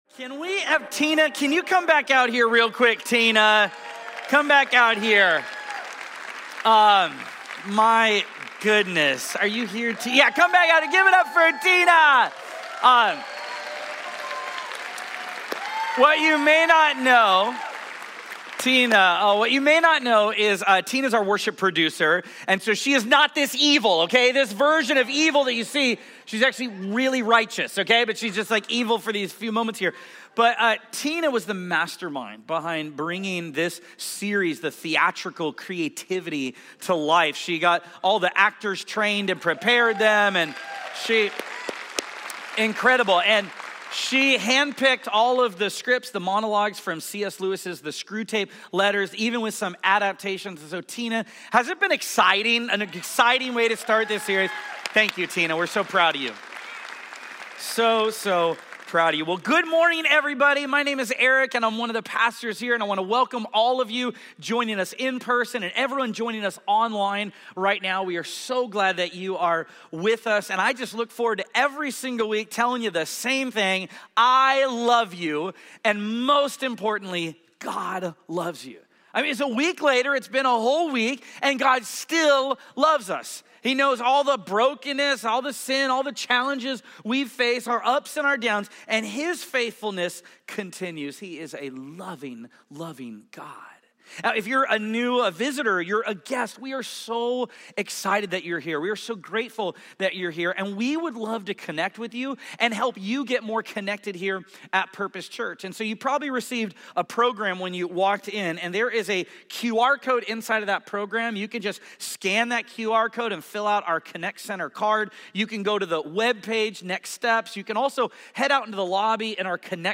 This week’s message wraps up The War You’re In series with a special Q&A on spiritual warfare.